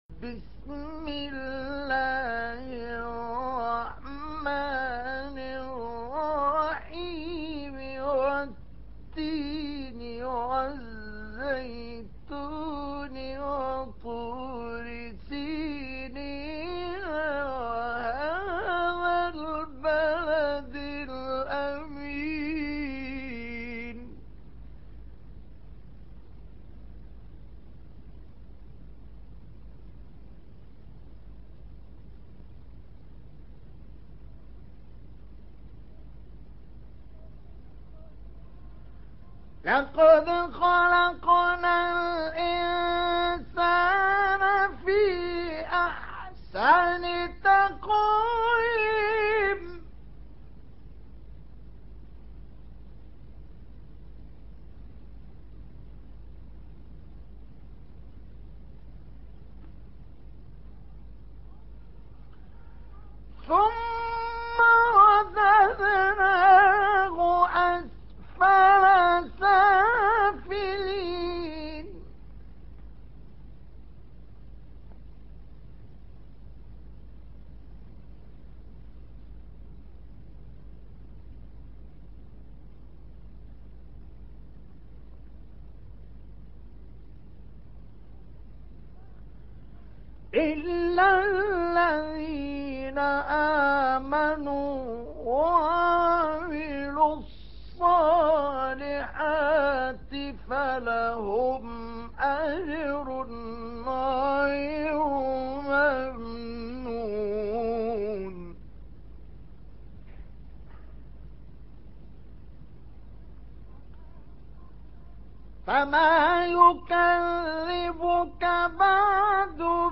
دانلود تلاوت سوره تین با صدای دلنشین استاد شیخ مصطفی اسماعیل
در این بخش از ضیاءالصالحین، تلاوت زیبای سوره تین را با صدای دلنشین استاد شیخ مصطفی اسماعیل به مدت 2 دقیقه با علاقه مندان به اشتراک می گذاریم.
قرائت مجلسی